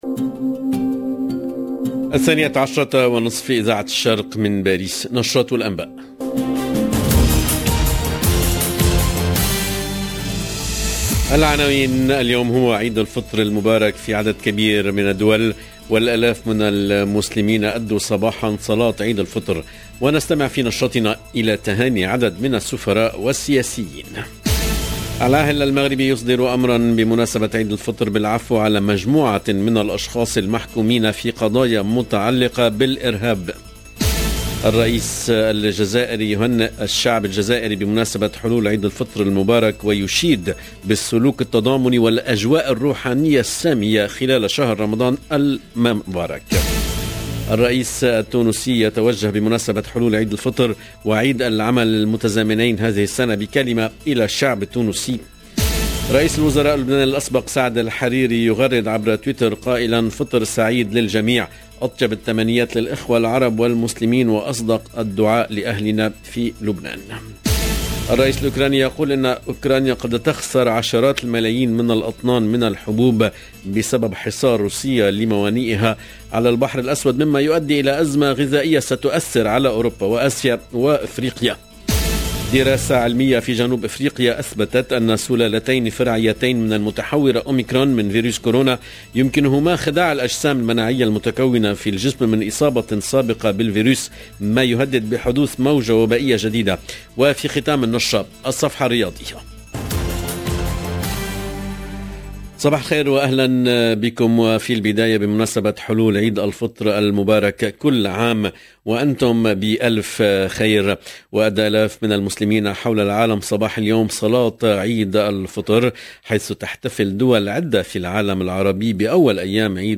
LE JOURNAL DE 12H 30 EN LANGUE ARABE DU 2/5/2022
EDITION DU JOURNAL EN LANGUE ARABE DU 2/5/2022